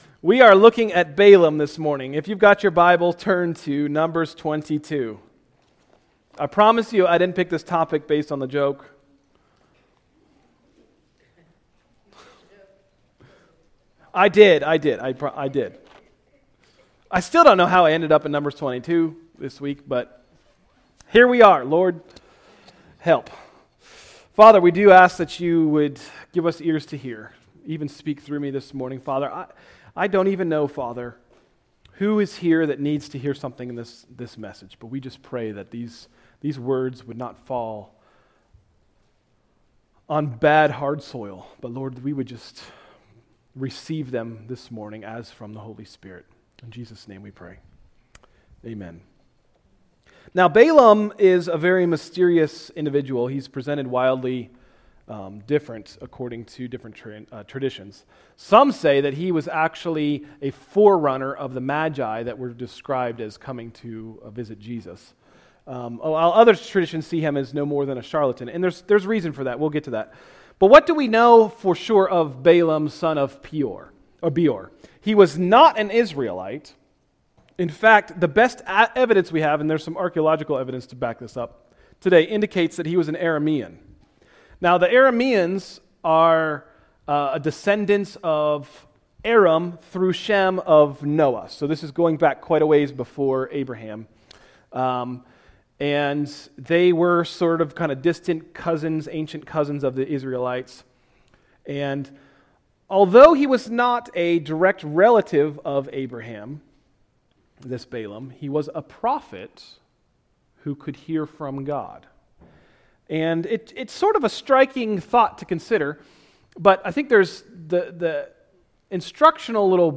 Message: “When Your Donkey Speaks” – Tried Stone Christian Center